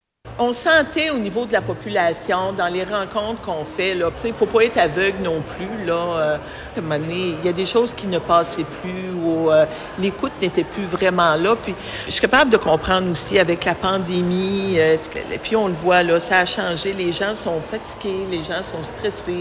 La ministre des Pêches et des Océans s’est entretenue avec les médias à ce sujet jeudi, lors d’une rencontre à Gaspé.